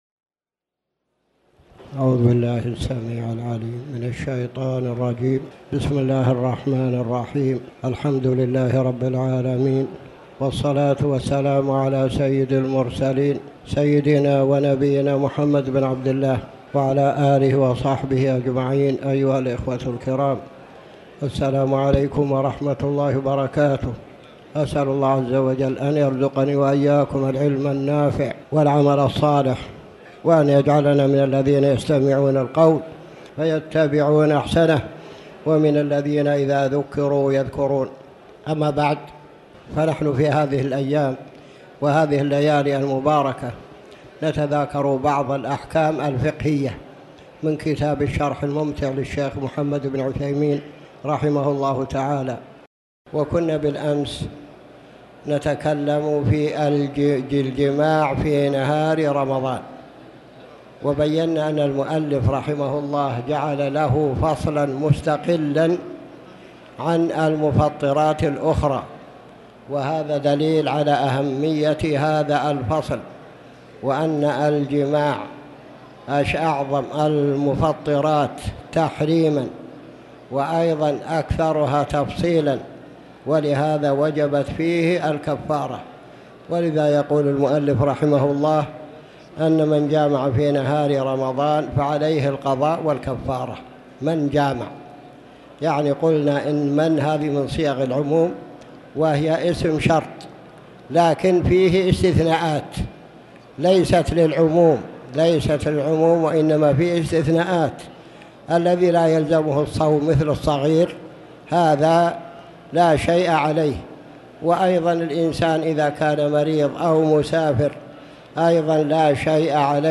تاريخ النشر ٢٥ جمادى الأولى ١٤٣٩ هـ المكان: المسجد الحرام الشيخ